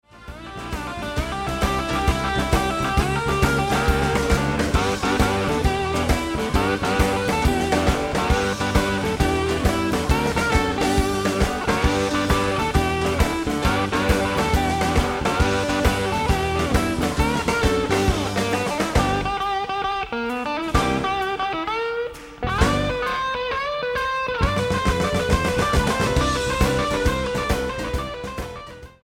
STYLE: Blues